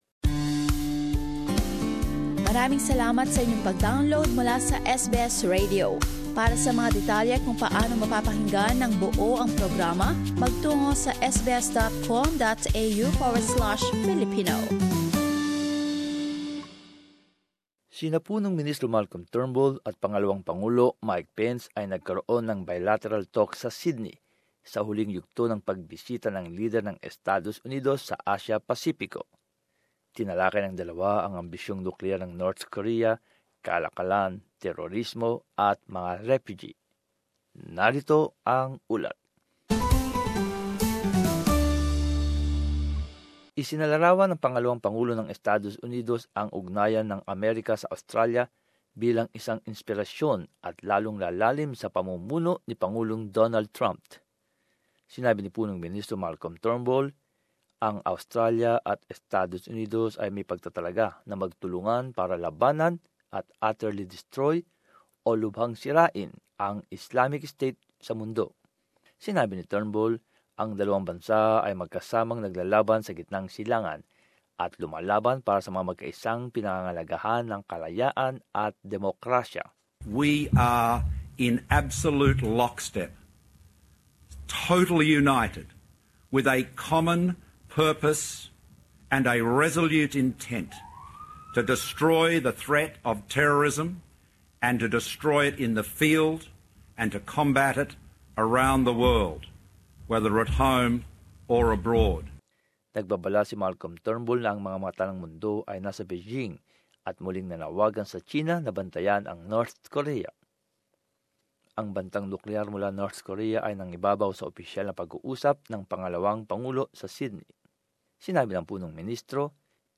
Narito ang ulat